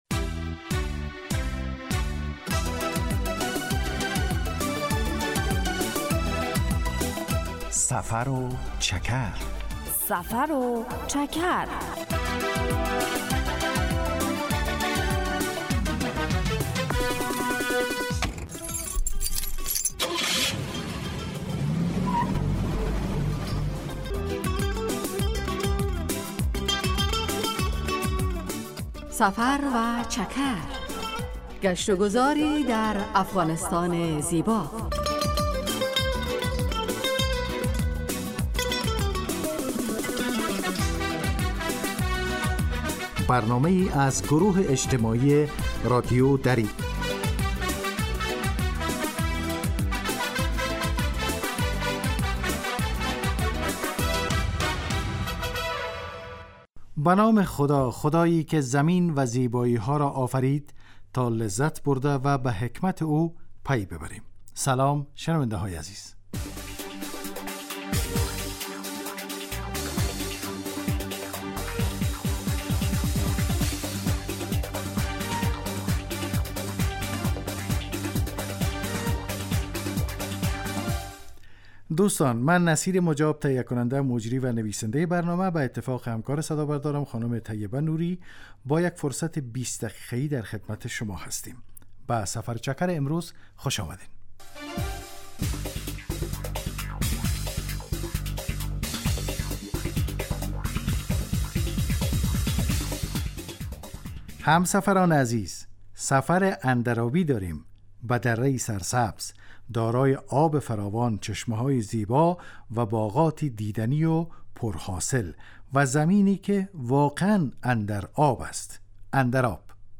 هدف: آشنایی با فرهنگ عامه و جغرافیای شهری و روستایی افغانستان که معلومات مفید را در قالب گزارش و گفتگو های جالب و آهنگ های متناسب تقدیم می کند.